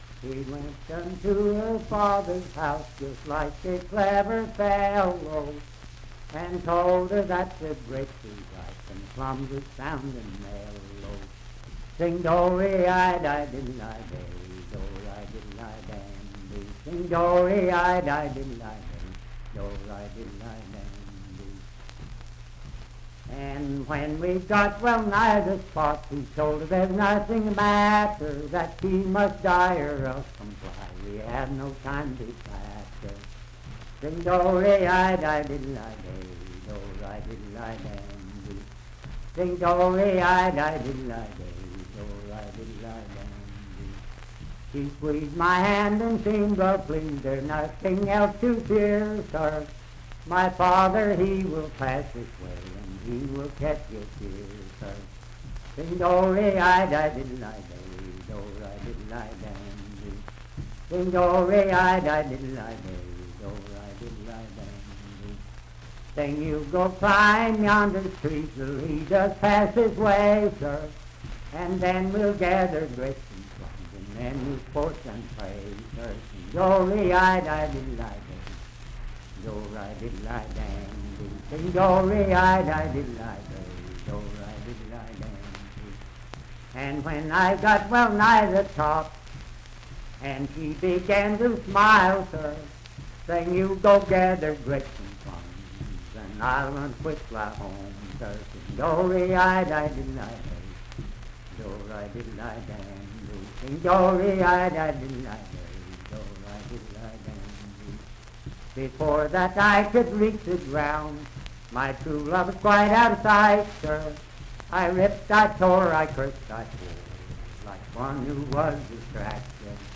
Unaccompanied vocal music and folktales
Voice (sung)
Parkersburg (W. Va.), Wood County (W. Va.)